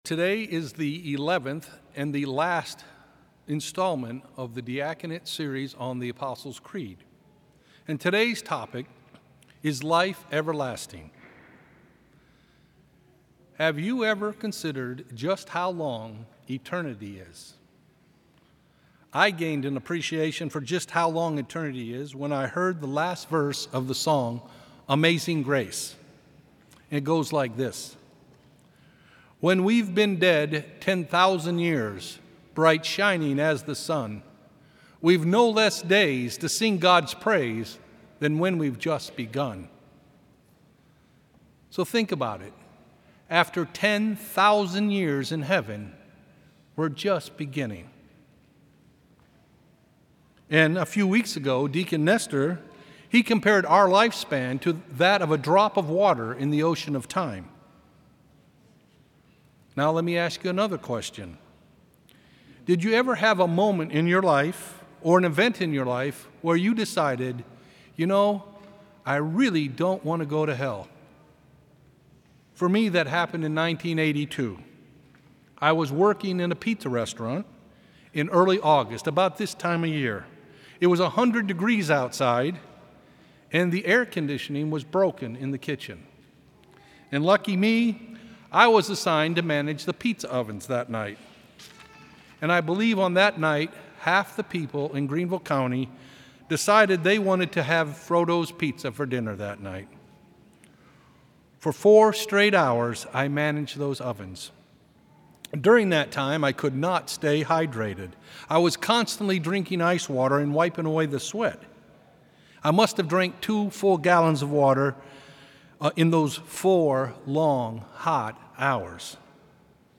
Homily
From Series: "Homilies"